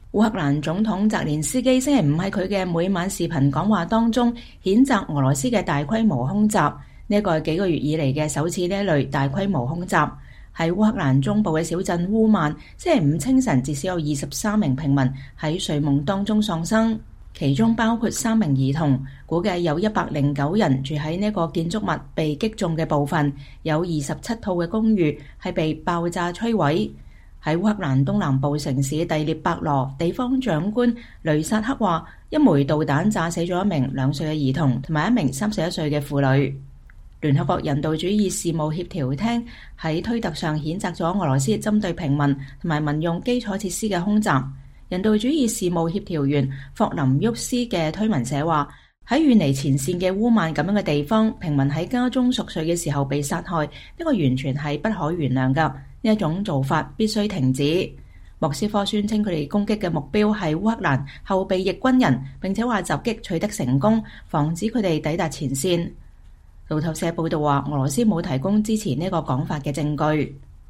烏克蘭總統弗拉基米爾·澤連斯基(Volodymyr Zelenskyy)週五在他的每晚視頻講話中譴責俄羅斯的大規模空襲，這是數月來的首次此類大規模空襲。